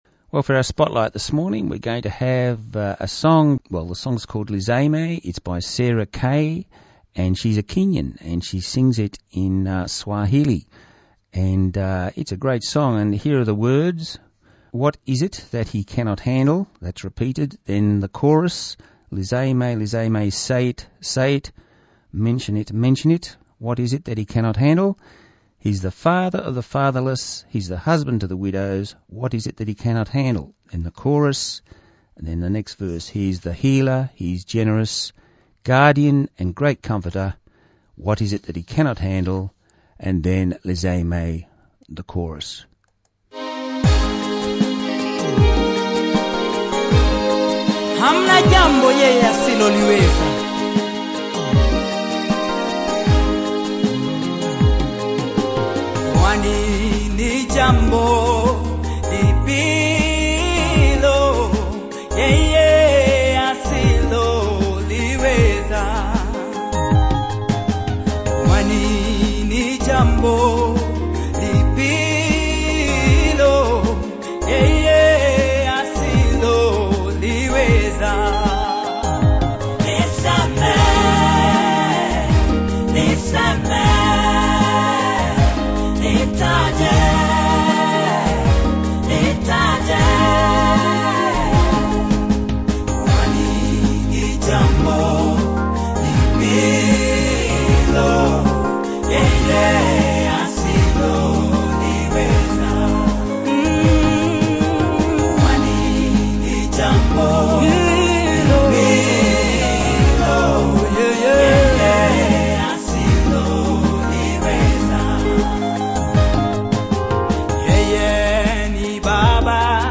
Christian, songs,
a gospel song of praise in Swahili.